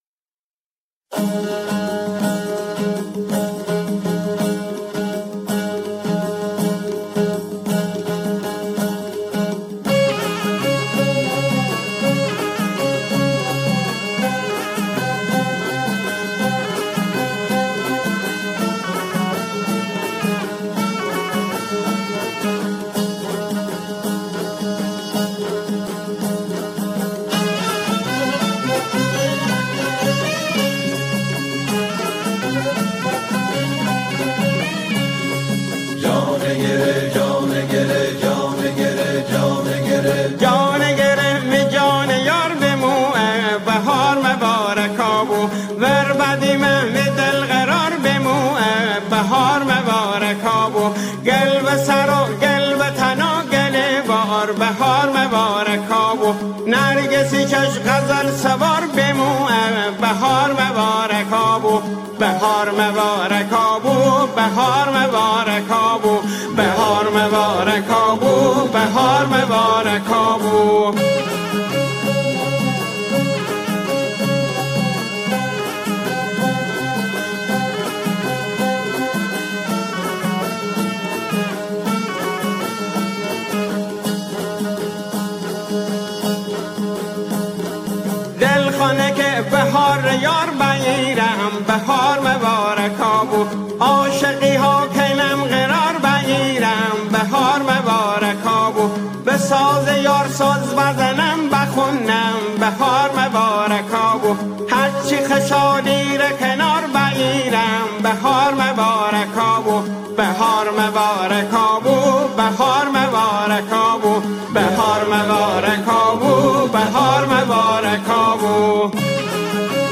گروهی از همخوانان
به گویش مازندرانی